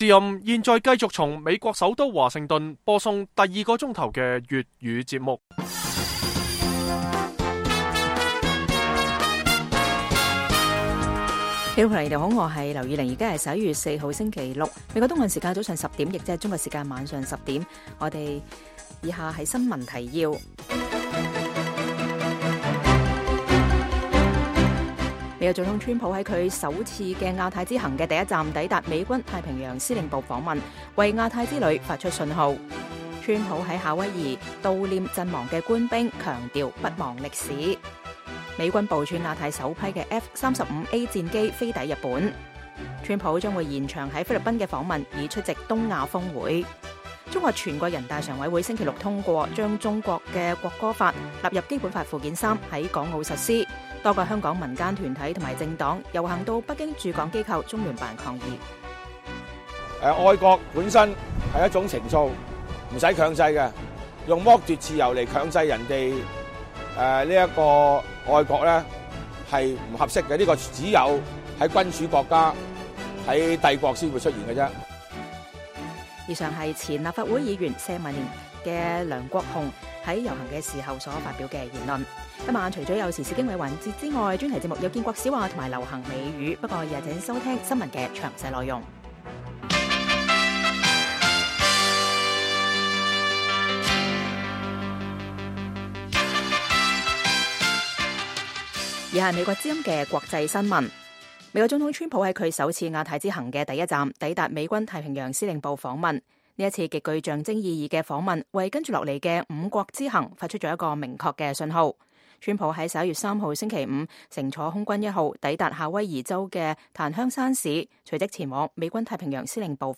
北京時間每晚10－11點 (1400-1500 UTC)粵語廣播節目。內容包括國際新聞、時事經緯和英語教學。